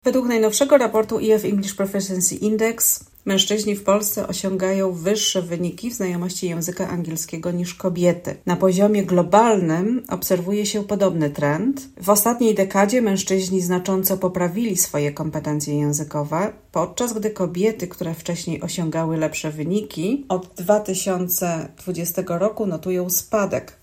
O tym, jak sytuacja wygląda obecnie, mówi anglistka